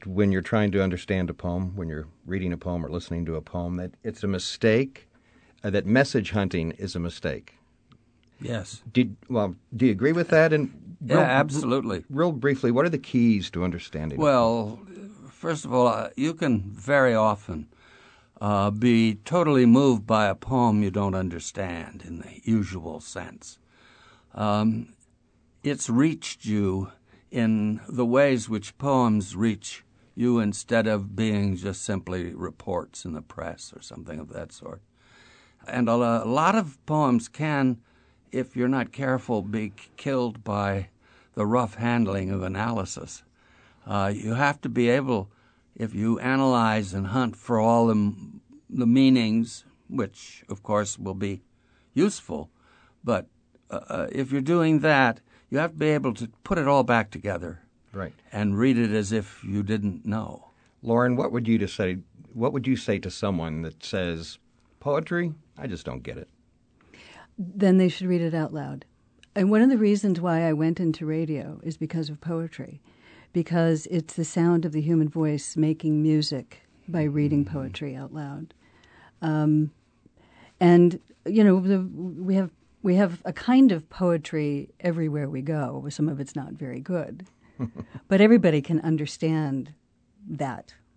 This segment from the Cityscape radio program promoted the "Published and Perished" benefit for the St. Louis Poetry Center.